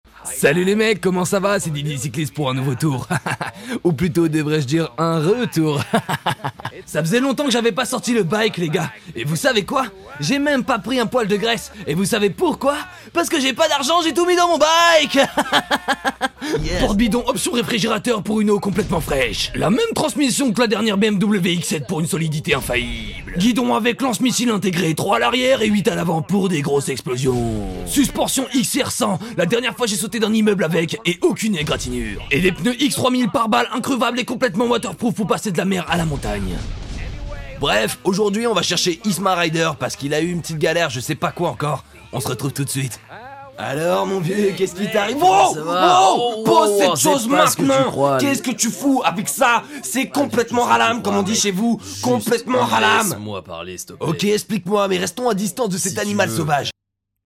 Voice over
21 - 38 ans - Baryton